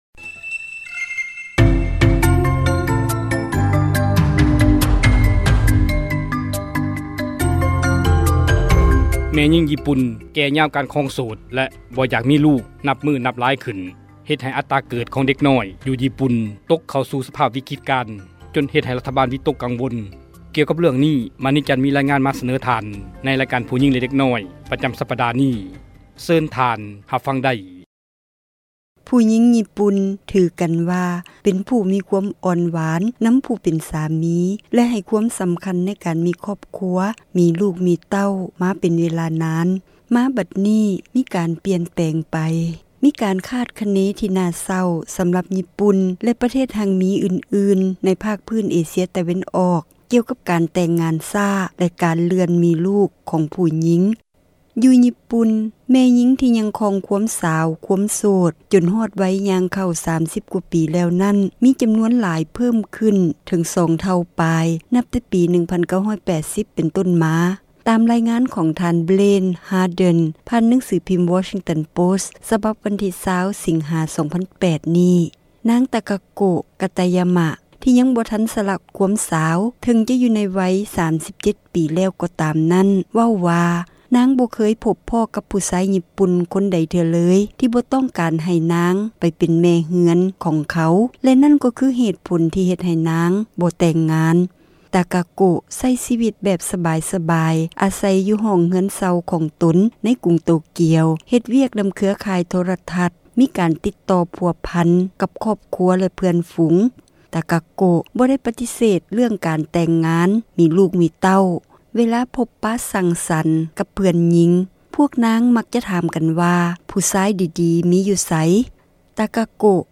ສເນີ ໂດຍ